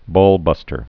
(bôlbŭstər)